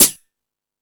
Hip House(19).wav